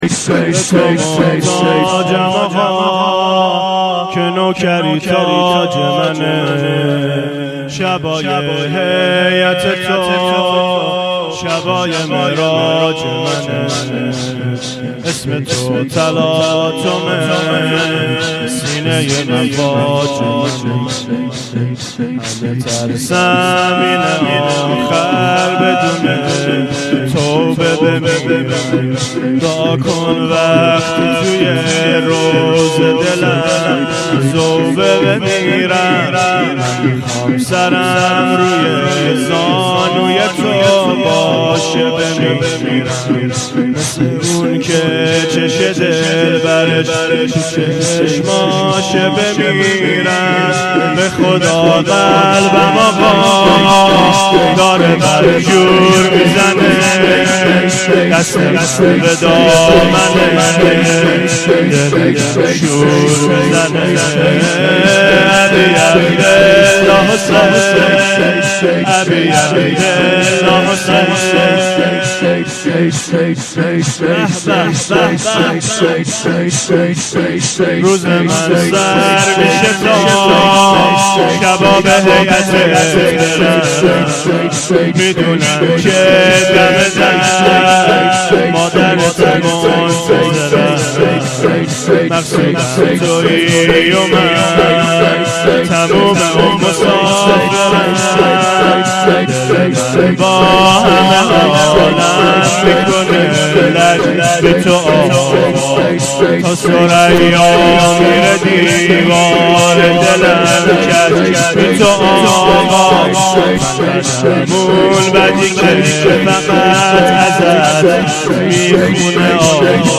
شور دوم